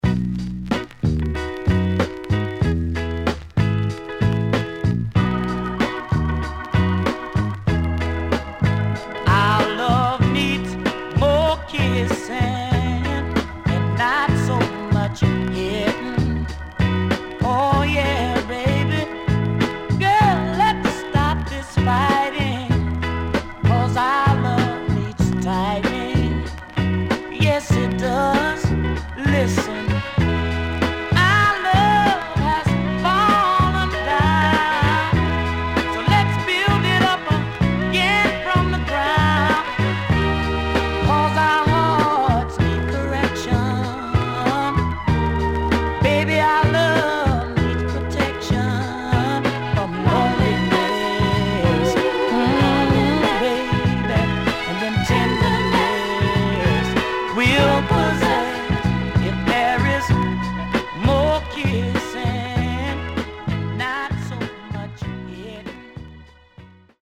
CONDITION SIDE A:VG(OK)
SIDE A:所々チリノイズがあり、少しプチノイズ入ります。